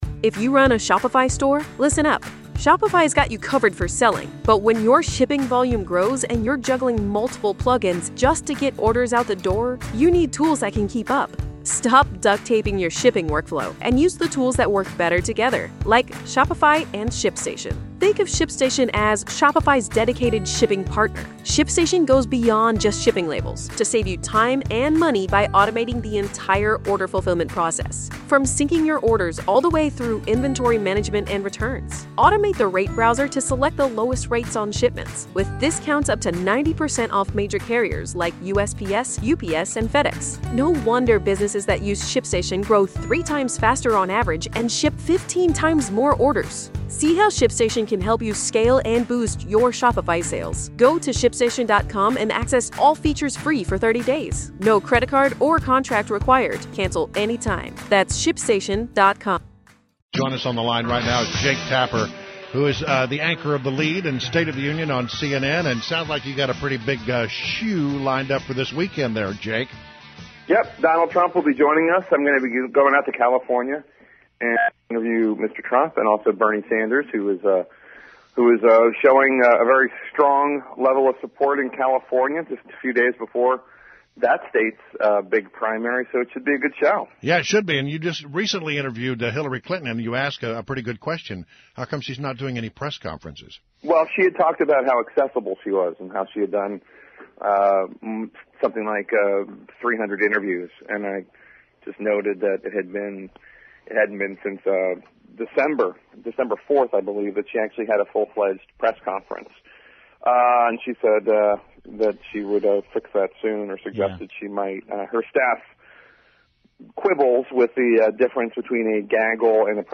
INTERVIEW — JAKE TAPPER – ANCHOR of “THE LEAD” and “STATE OF THE UNION” on CNN